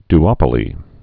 (d-ŏpə-lē, dy-)